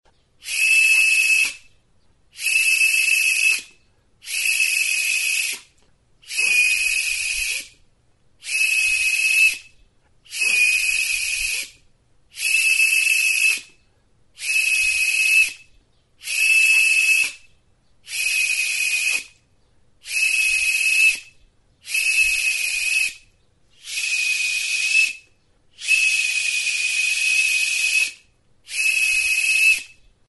TXULUBITA | Soinuenea Herri Musikaren Txokoa
Enregistr� avec cet instrument de musique.
Makalezko makilarekin egindako txulubita da.